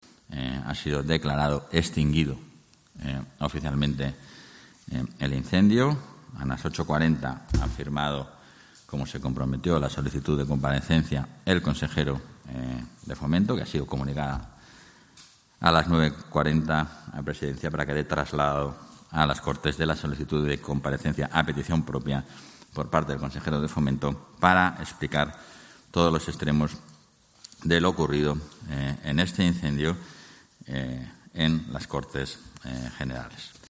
Francisco Igea. Comparecencia Suárez-Quiñones por el incendio de Navalacruz